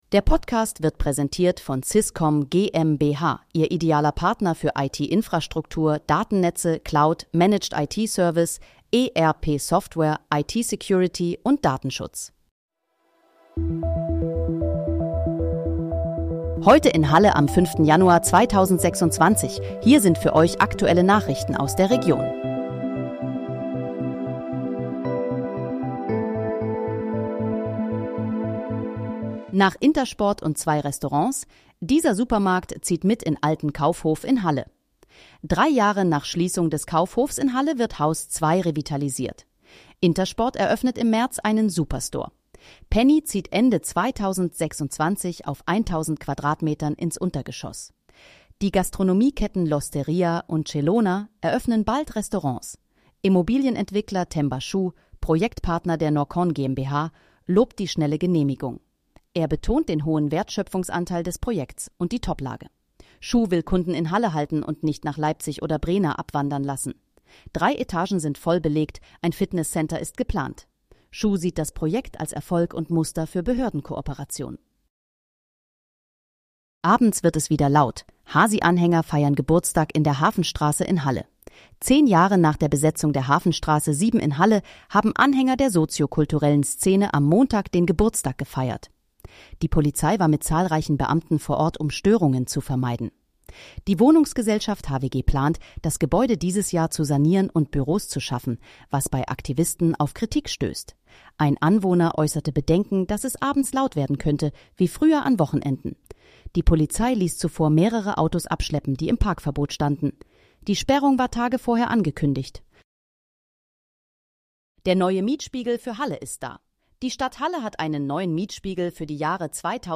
Nachrichten